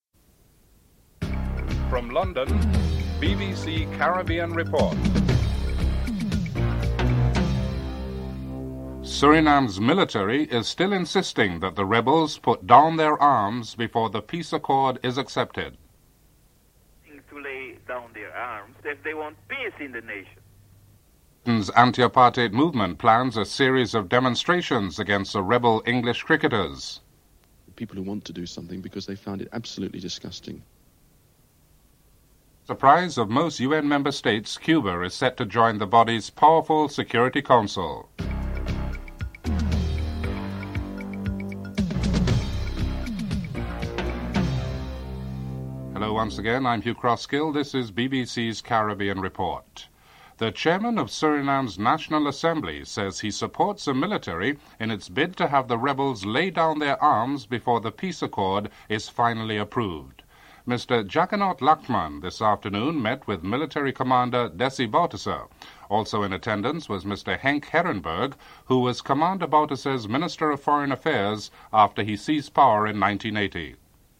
1. Headlines (00:00-00:40)
3. Financial news (04:32-05:25)